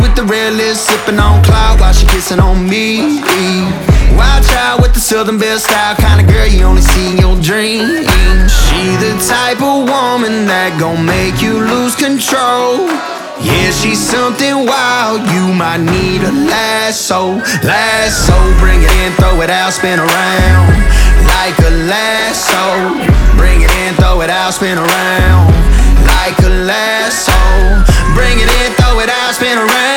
2025-04-25 Жанр: Кантри Длительность